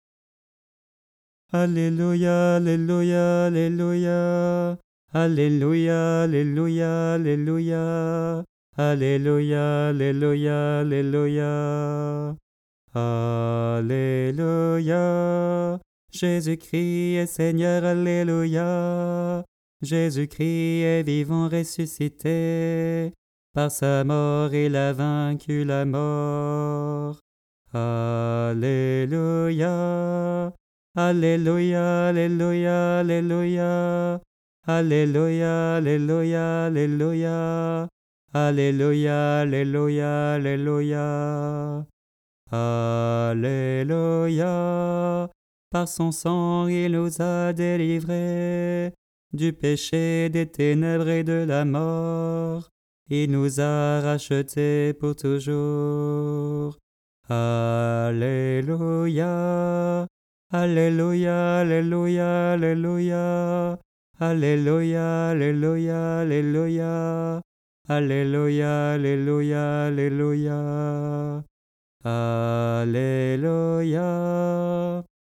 Tradition polonaise
Voix chantée (MP3)COUPLET/REFRAIN
ALTO